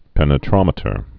(pĕnĭ-trŏmĭ-tər) also pen·e·tram·e·ter (-trămĭ-tər)